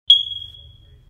Smoke Alarm Beep Ultra Bass Boosted Sound Effect Download: Instant Soundboard Button